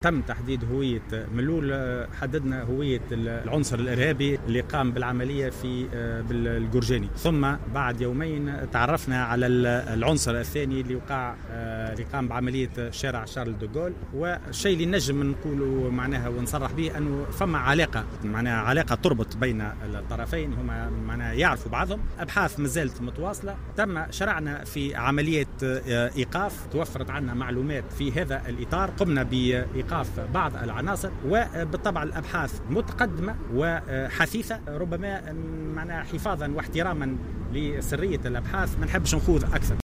وأضاف الفوراتي في تصريح لوكالة تونس افريقيا للأنباء على هامش اشرافه على اعطاء اشارة انطلاق برنامج العطلة الامنة بالمصلحة الاولى بالطريق السيارة "أ 1" تركي (ولاية نابل)، إن الابحاث متقدمة وحثيثة، وقد تم في مرحلة اولى تحديد هوية العنصر الارهابي الذي قام بعملية القرجاني وبعد يومين تحديد هوية العنصر الارهابي الثاني.